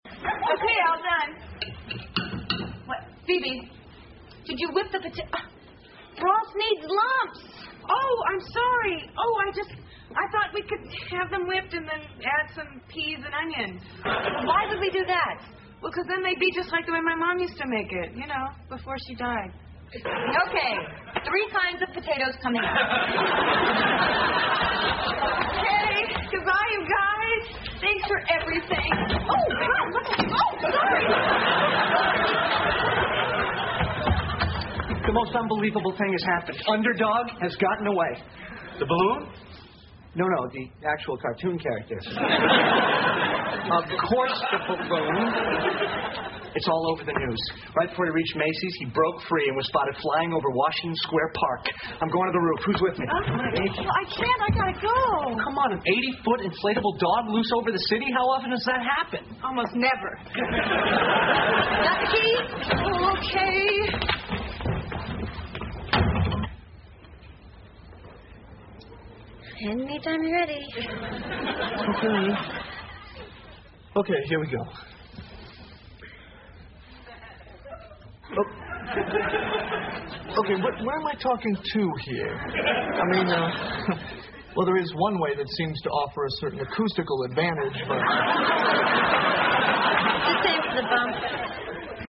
在线英语听力室老友记精校版第1季 第107期:气球飞走了(8)的听力文件下载, 《老友记精校版》是美国乃至全世界最受欢迎的情景喜剧，一共拍摄了10季，以其幽默的对白和与现实生活的贴近吸引了无数的观众，精校版栏目搭配高音质音频与同步双语字幕，是练习提升英语听力水平，积累英语知识的好帮手。